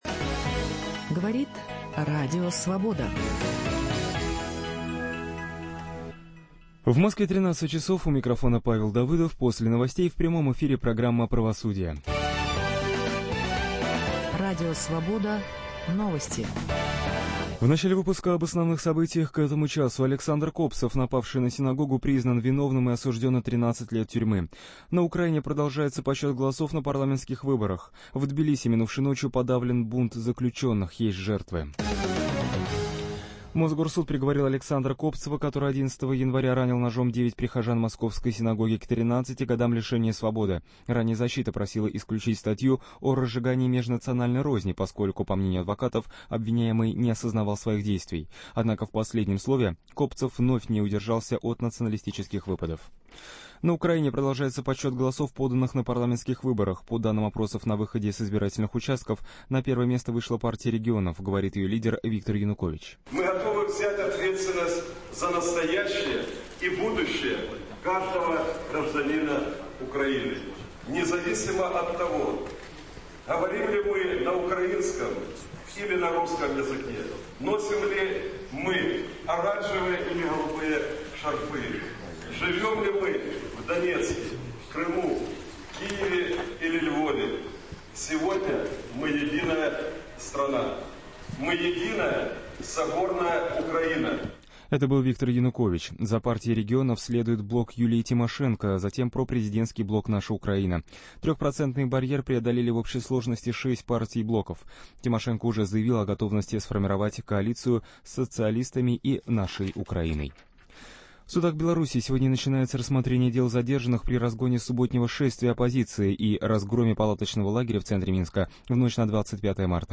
Чьи интересы сегодня защищает судебная власть? На эти вопросы отвечают гости в студии РС профессор юридического факультета МГУ им.